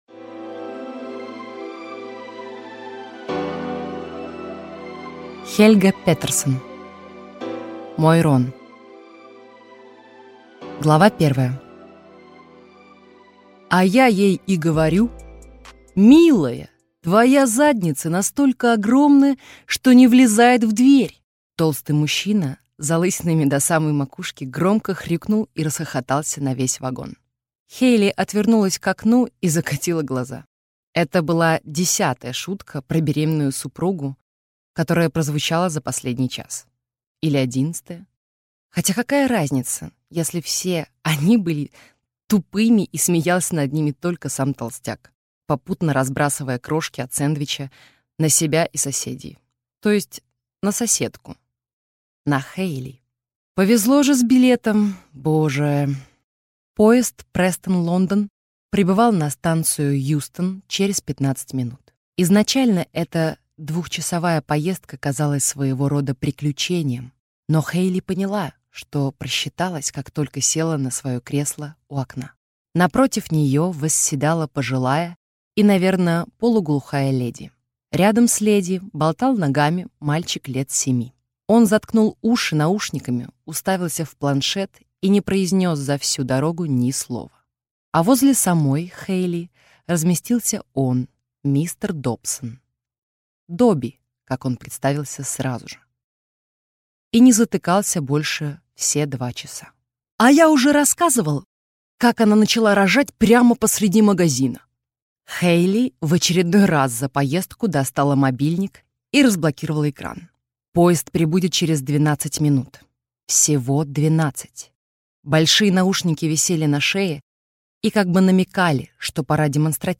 Аудиокнига Мой Рон | Библиотека аудиокниг